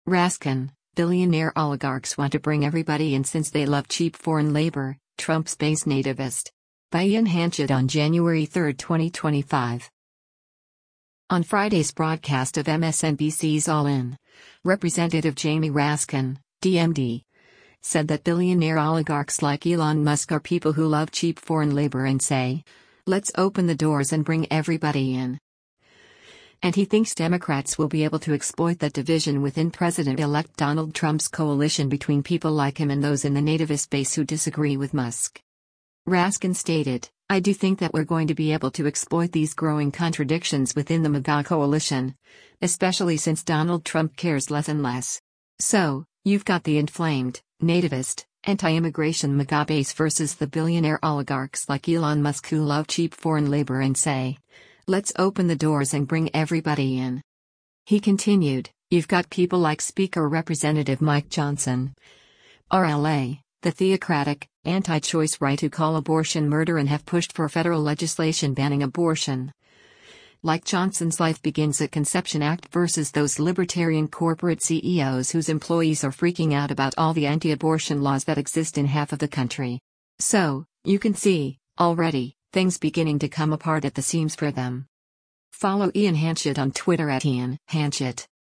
On Friday’s broadcast of MSNBC’s “All In,” Rep. Jamie Raskin (D-MD) said that “billionaire oligarchs like Elon Musk” are people who “love cheap foreign labor and say, let’s open the doors and bring everybody in.” And he thinks Democrats will be able to exploit that division within President-Elect Donald Trump’s coalition between people like him and those in the “nativist” base who disagree with Musk.